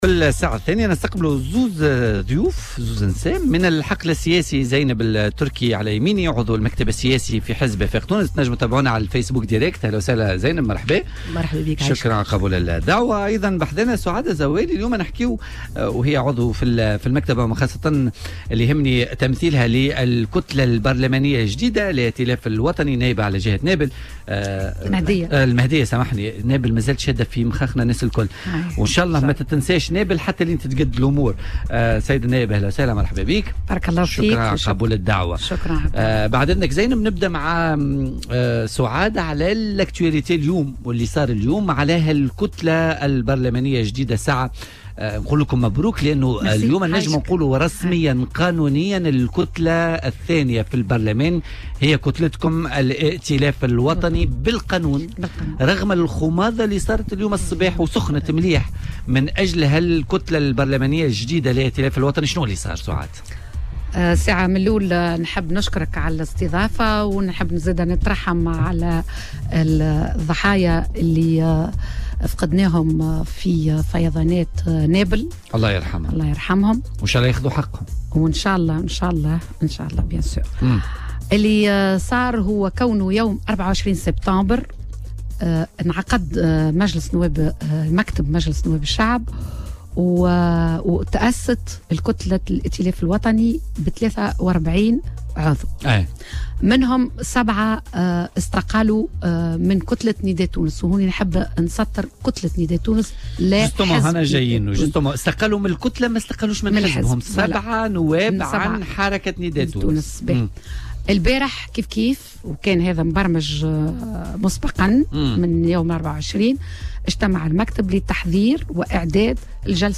وأوضحت ضيفة بوليتيكا على "الجوهرة اف أم"،